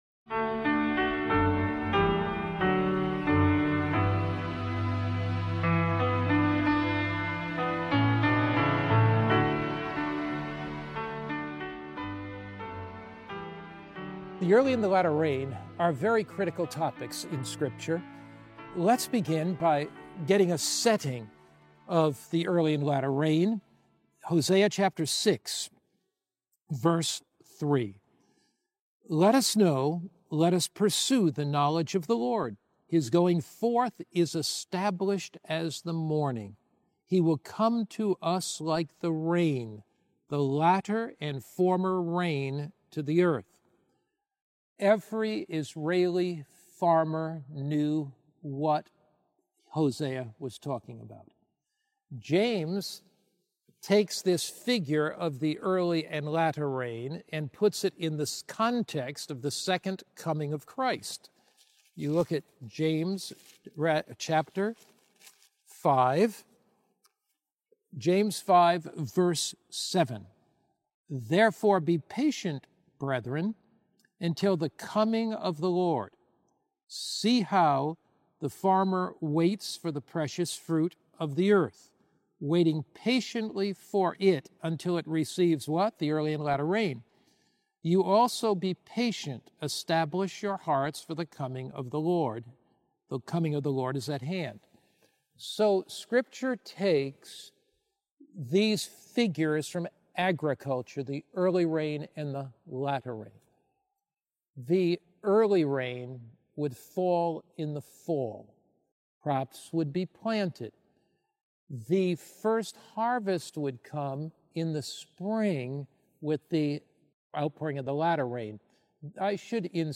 This sermon unveils the powerful symbolism of the early and latter rain, revealing how the Holy Spirit’s work began at Pentecost and will climax in an end-time outpouring that prepares the world for Christ’s return. Through biblical prophecy, history, and practical application, it calls believers to unity, prayer, and full surrender—so they may receive the Spirit’s fullness and join in God’s final harvest.